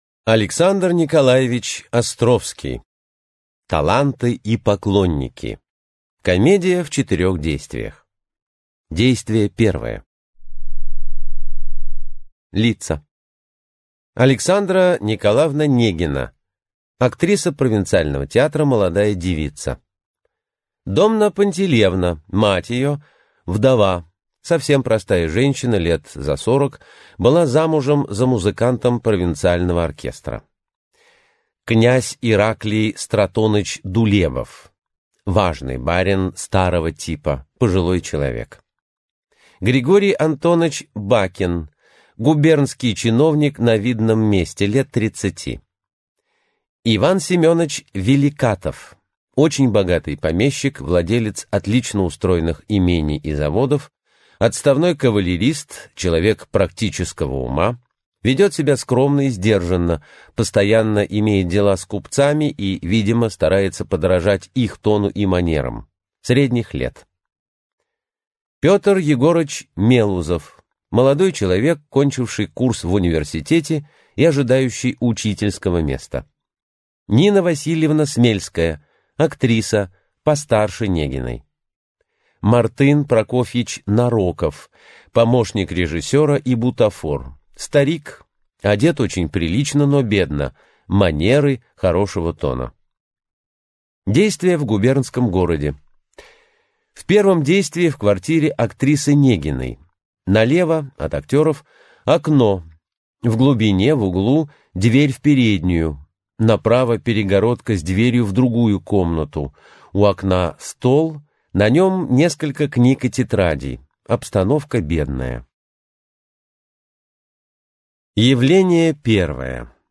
Аудиокнига Таланты и поклонники | Библиотека аудиокниг